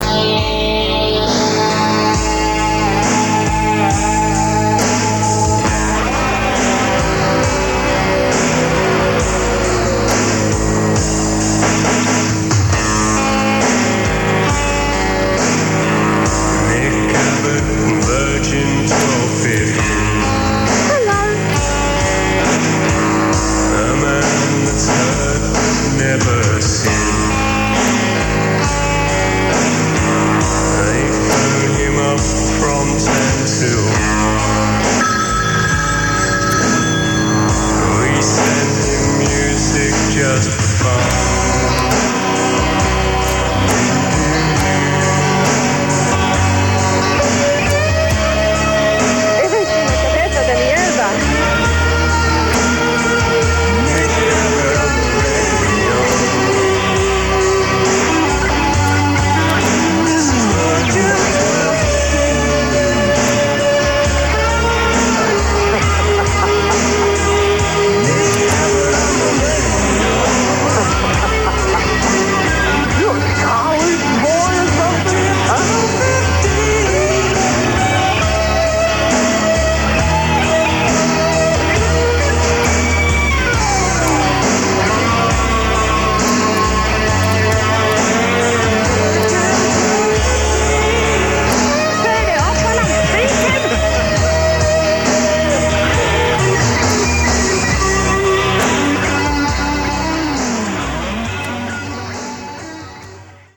These were made by fans of his show.